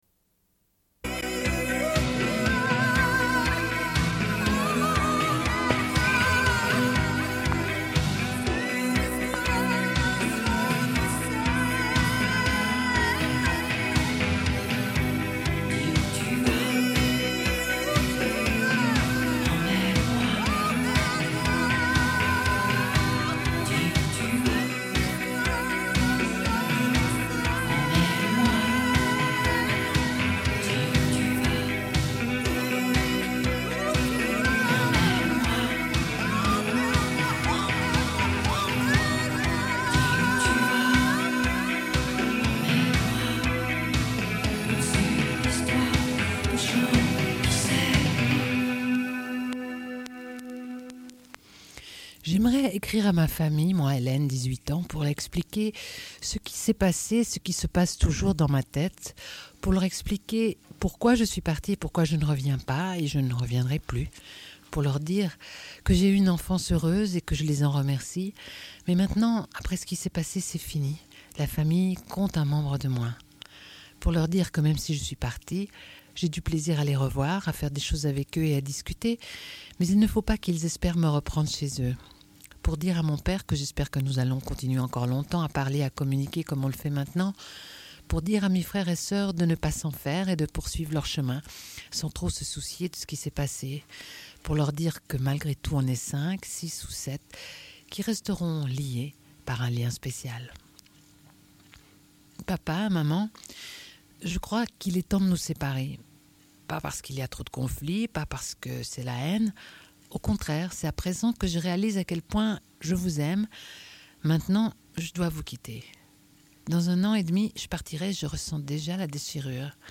Lecture d'extraits.
Une cassette audio, face B
Radio Enregistrement sonore